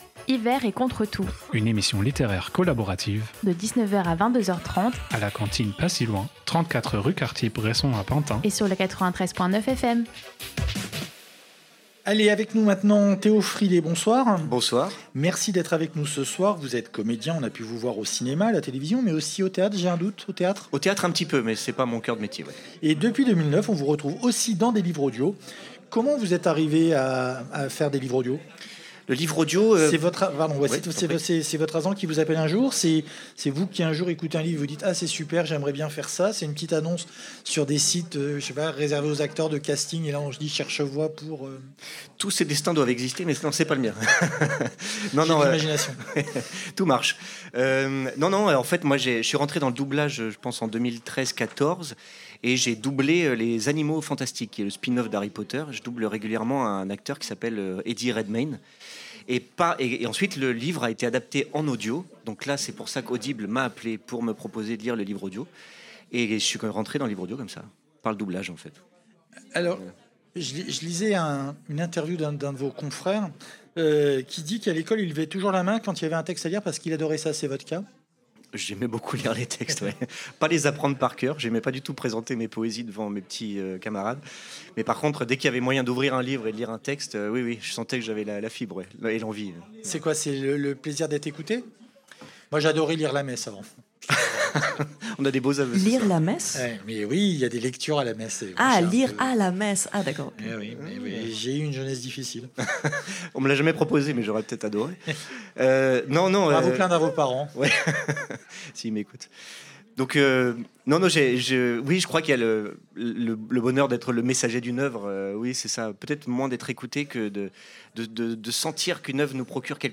Type Entretien
Pour fêter le solstice d'hiver (enfin ça c'est dans ma tête), Radio Campus Paris a organisé un HLM (hors les murs) à La cantine pas si loin de Pantin.